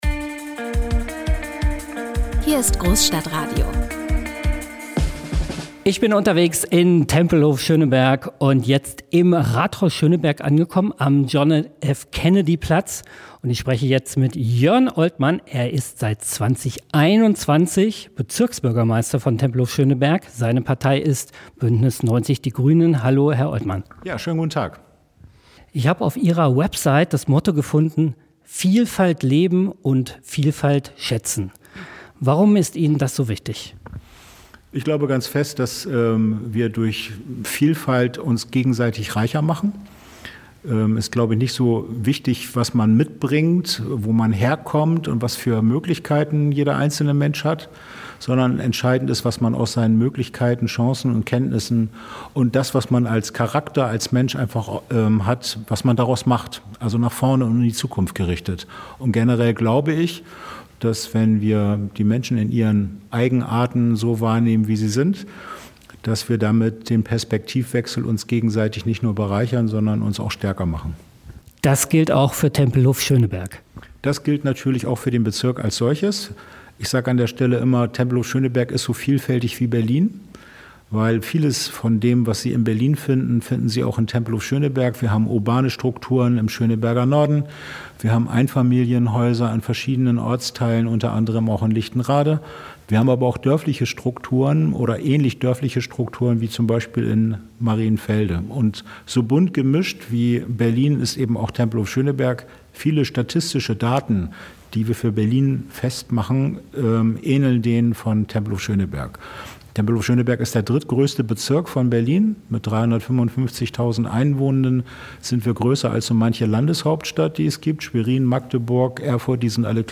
Als Bonus gibt es am Ende dieser Folge einen akustischen Rundgang mit Jörn Oltmann durch sein historisches Dienstzimmer, in dem schon John F. Kennedy den weltberühmten Satz "Ich bin ein Berliner" vor seinem Auftritt vor dem Rathaus Schöneberg im Jahr 1963 einübte.